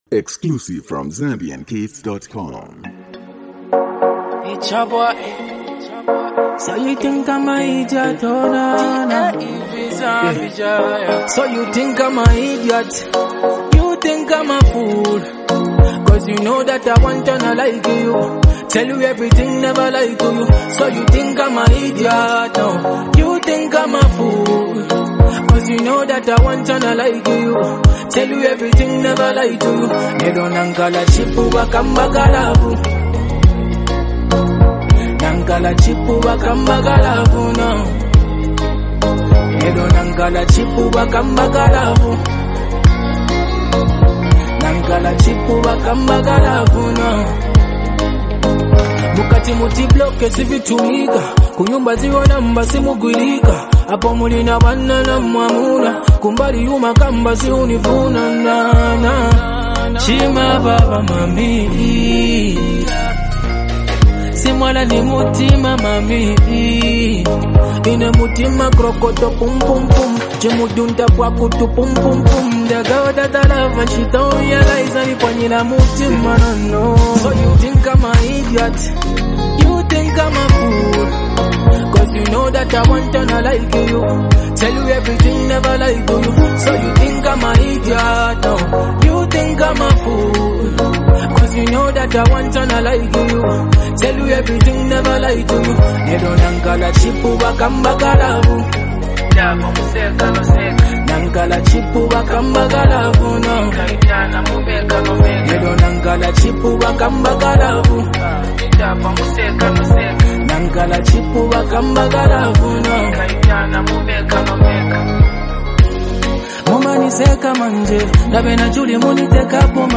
Zambian Music
a soulful and emotionally rich song
smooth vocals and heartfelt lyrics